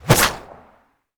bullet_leave_barrel_01.wav